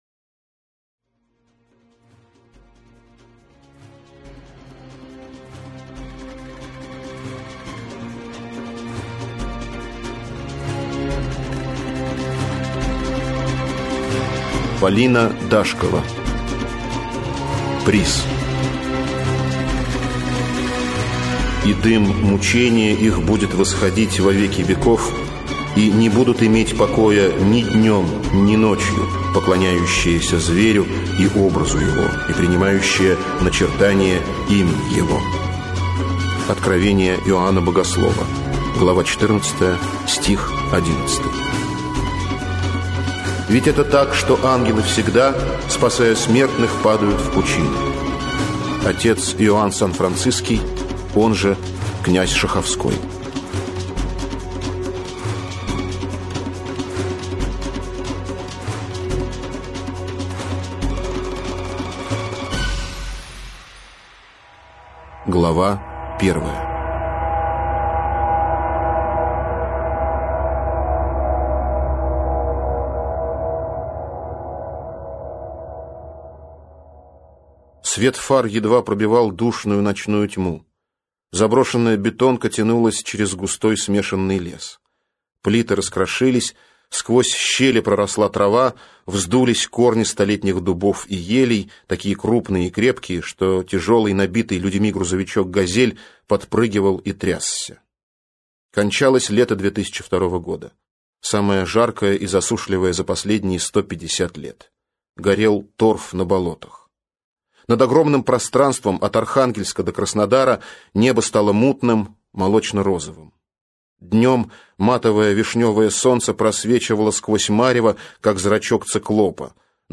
Аудиокнига Приз | Библиотека аудиокниг